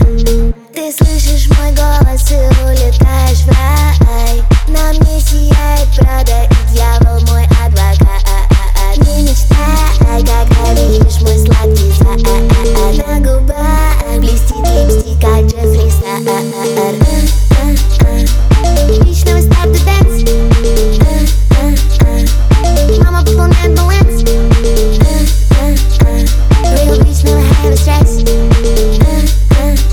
Жанр: Поп / Инди / Русские